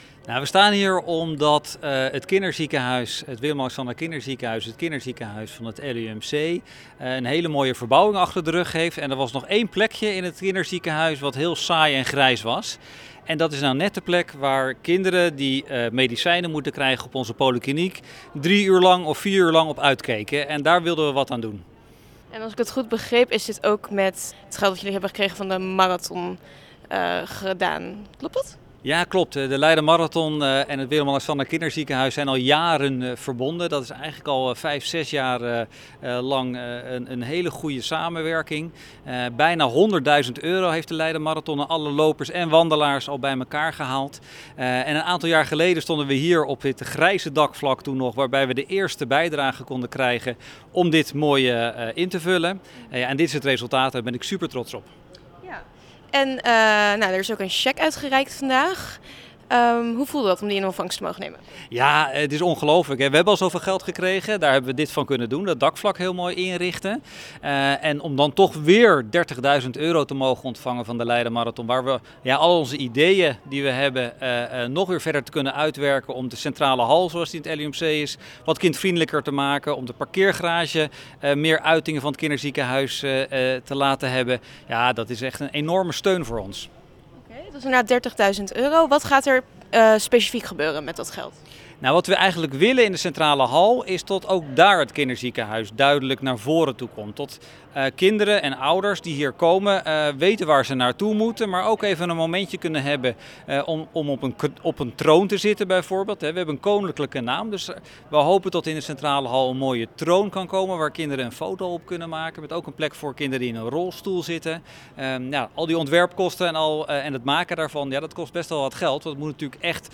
in gesprek met kindercardioloog